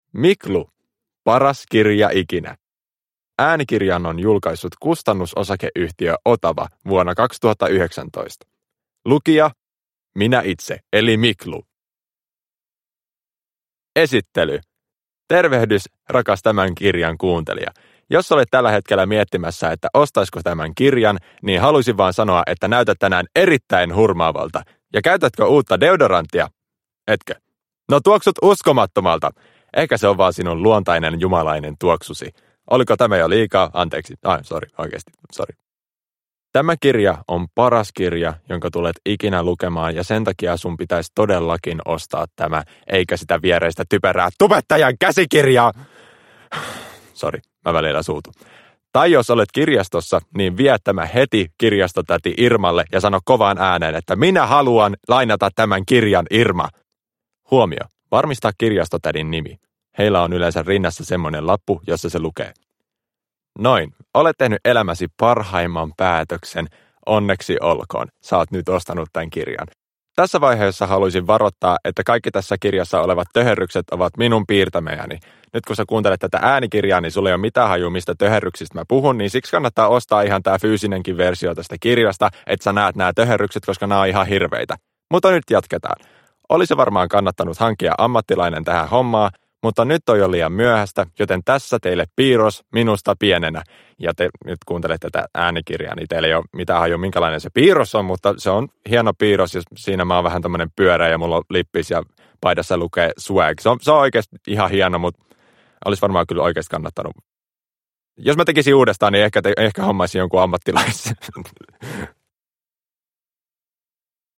Paras kirja ikinä – Ljudbok – Laddas ner